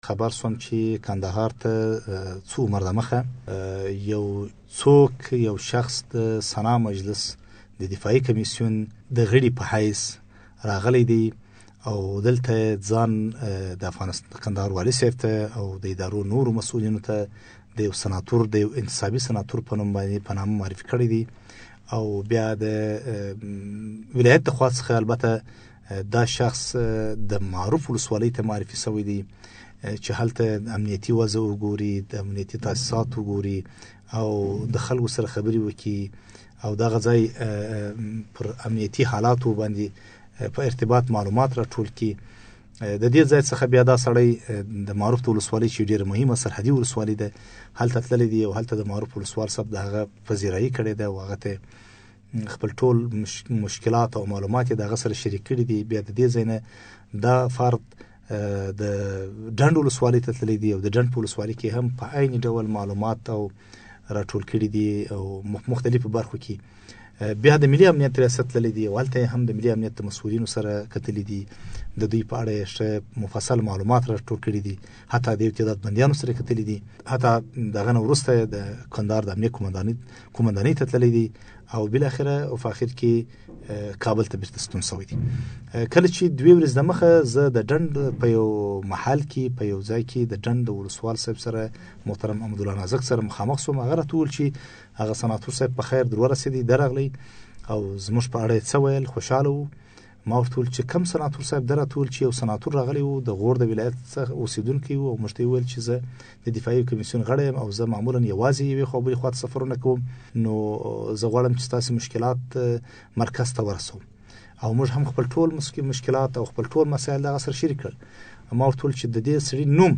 له بسم الله افغانمل سره مرکه